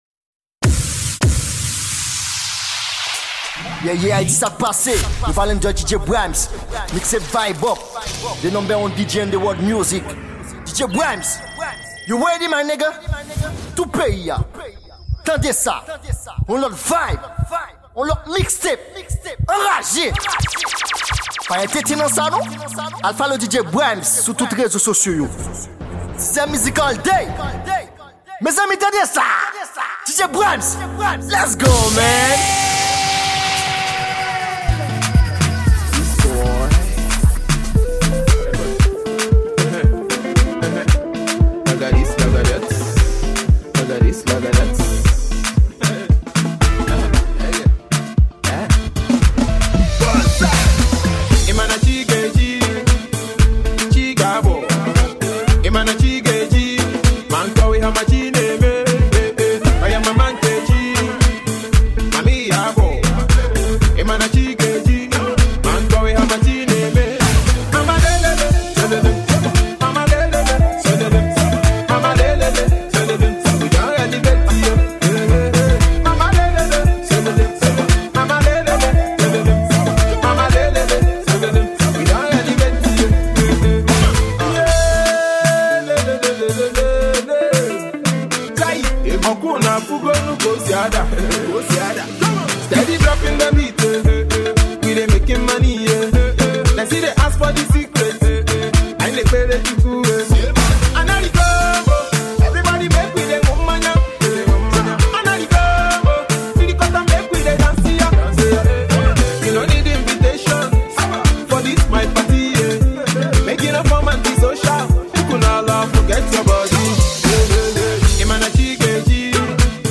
Genre : MIXES